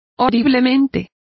Complete with pronunciation of the translation of horribly.